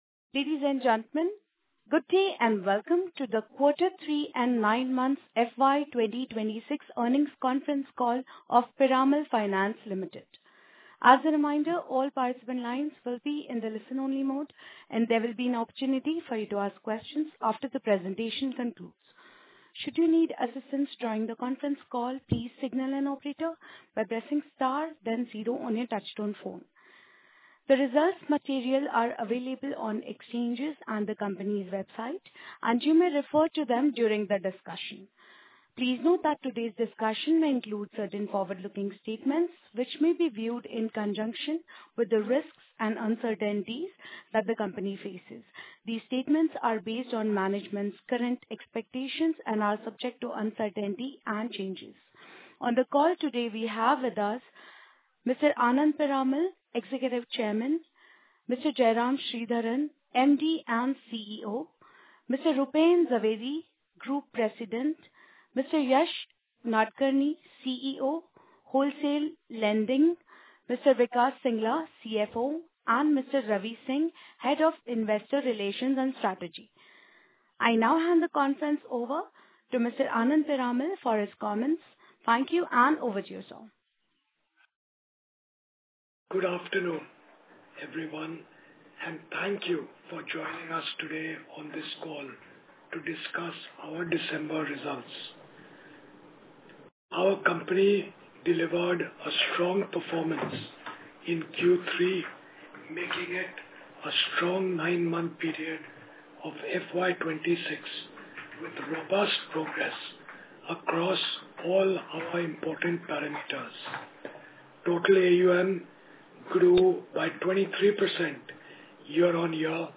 Result Presentation Press Release Historical Data Sheet Standalone Financial Results Consolidated Financial Results Earnings Conference Call Invite Earnings Conference Call Transcript Earnings Conference Call Audio Recording